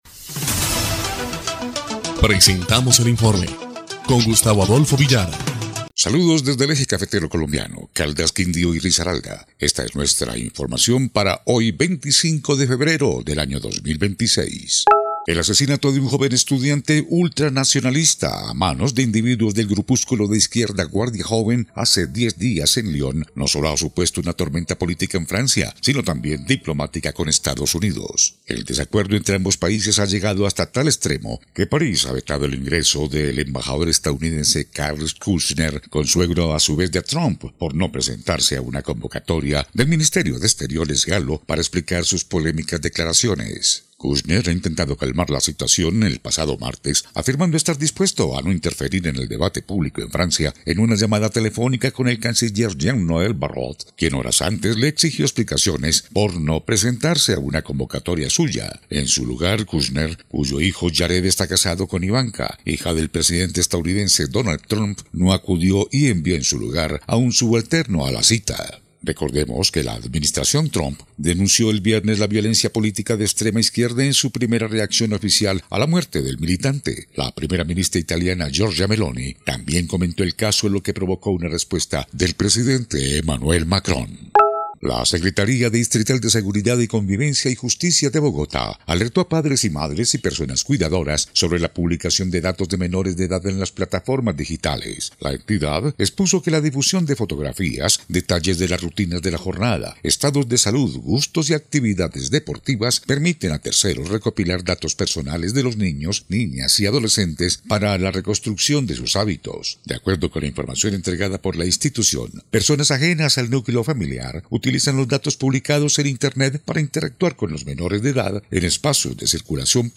EL INFORME 3° Clip de Noticias del 25 de febrero de 2026